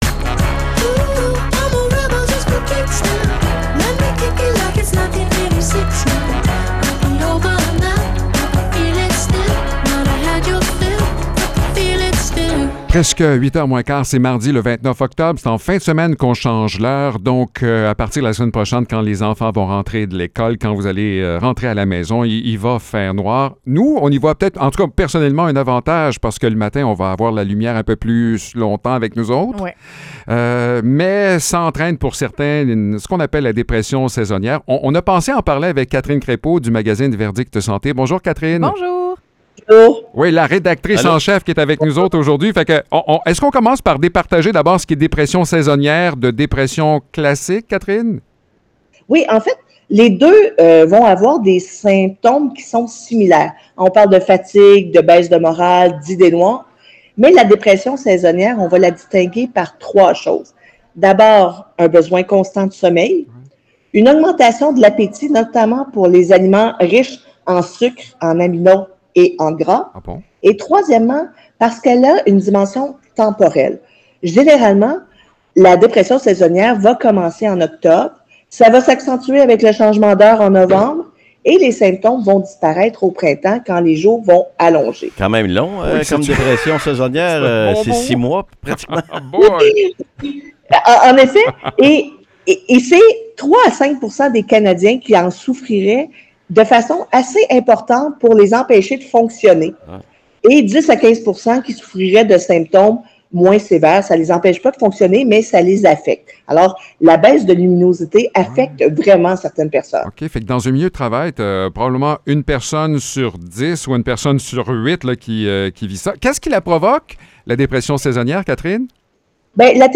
Chronique Verdict Santé